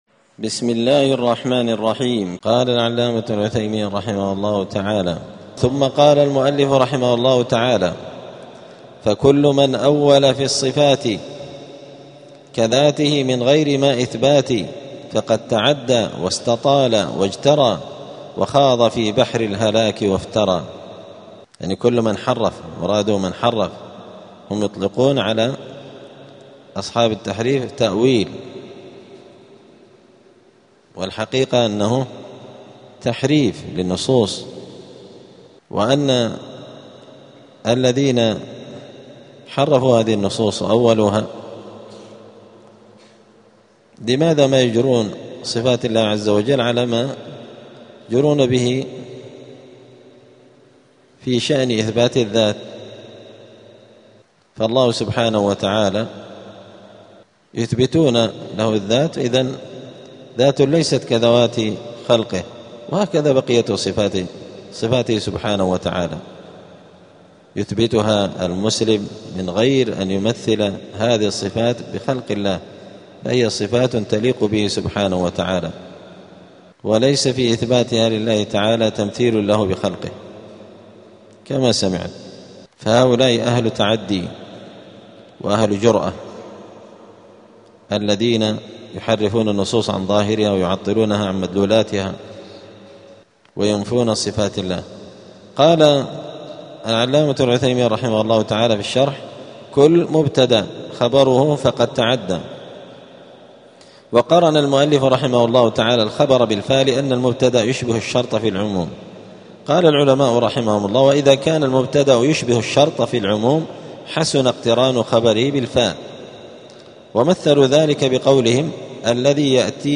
دار الحديث السلفية بمسجد الفرقان قشن المهرة اليمن
26الدرس-السادس-والعشرون-من-شرح-العقيدة-السفارينية.mp3